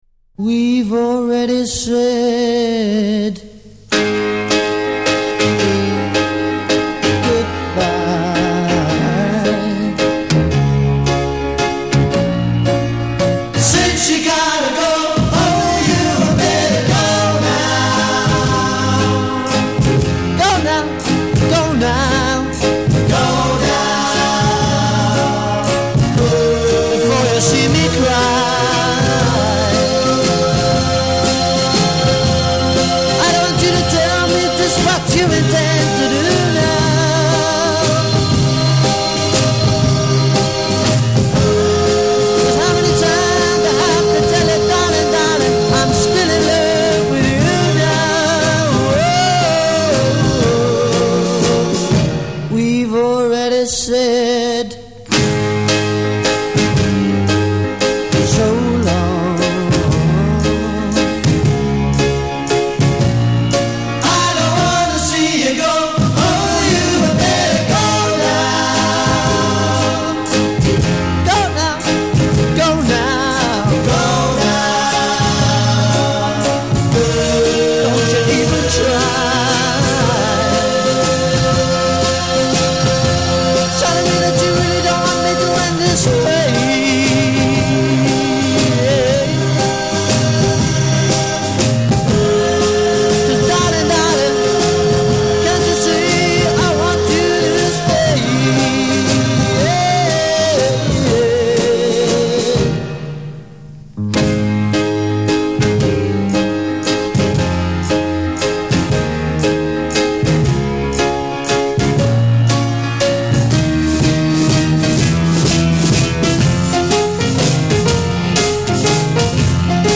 Recorded at the Marquee Club, London.
guitar, vocals
drums
piano, vocals
bass guitar
Verse part 1 0:00 8+8 Begins with free time statement;
chord progression built around descending bass line   a
part 2 0: 16 solo vocal over sustaining chorus b
Verse part 1 : 8 ensemble accompaniment
part 2 : 17 piano solo
Verse part 1 : 8 increased drum roll activity a'
Coda   : 7 emphasize hook c